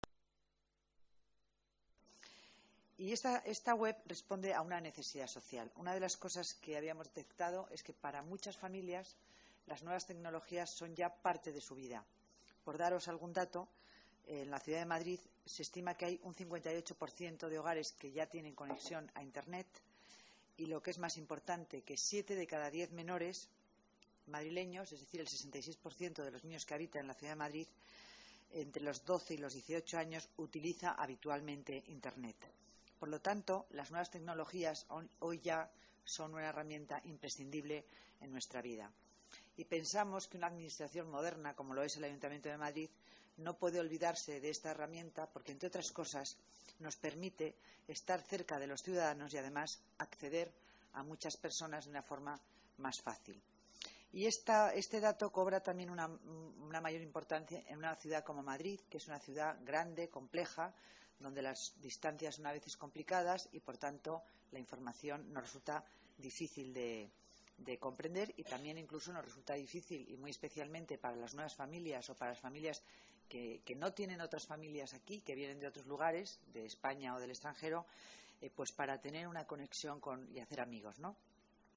Más archivos multimedia La delegada de Familia y Servicios Sociales, Concepción Dancausa, habla del Día Internacional de la Familia Declaraciones de Dancausa sobre las familias en Madrid Vídeo familiasenmadrid. org